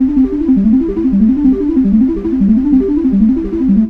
drone2.wav